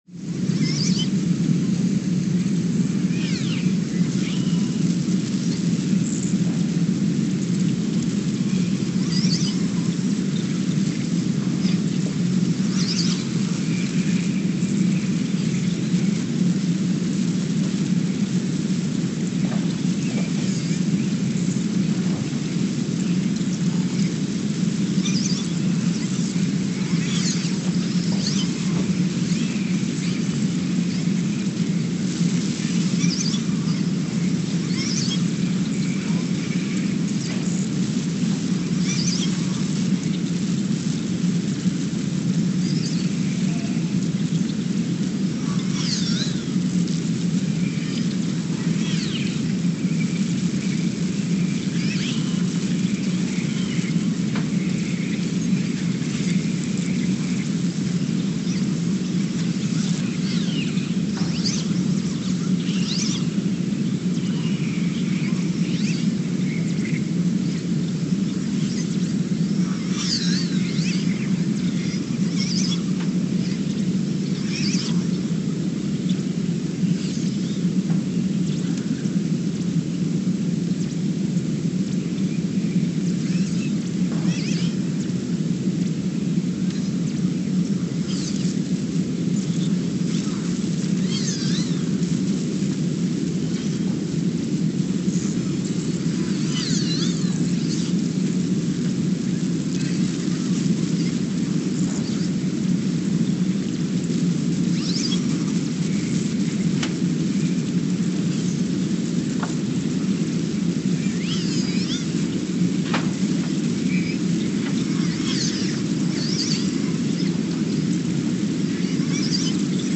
Ulaanbaatar, Mongolia (seismic) archived on January 14, 2024
No events.
Station : ULN (network: IRIS/USGS) at Ulaanbaatar, Mongolia
Sensor : STS-1V/VBB
Speedup : ×900 (transposed up about 10 octaves)
SoX post-processing : highpass -2 90 highpass -2 90